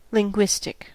Ääntäminen
IPA : /lɪŋˈɡwɪstɪk/